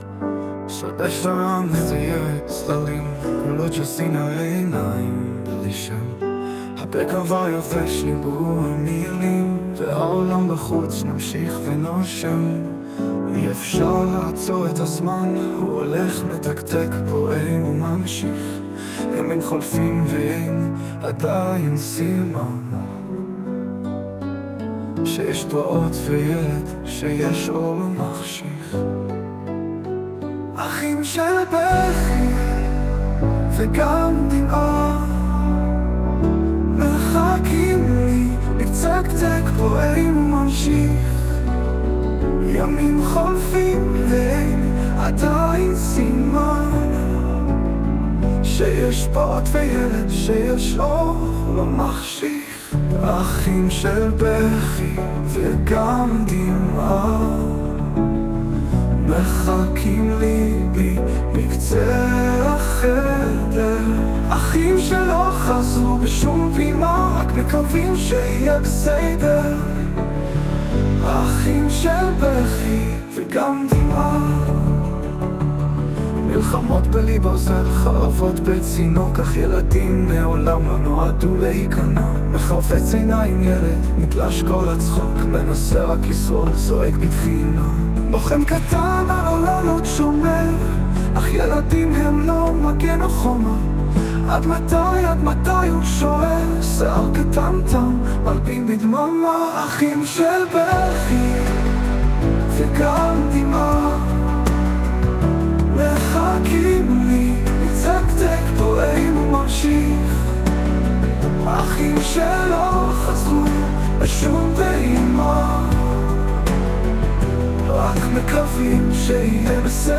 ישנם חסרונות רבים היכולים להיגרם ע"י שירת רובוט.
לפעמים השיר מתחיל טוב. אבל פתאום, באמצע הפזמון, הוא החליט שמתחשק לו לחזור שוב על השורות בבית השני (ובמקרה כאן עוד במנגינה של הפזמון!).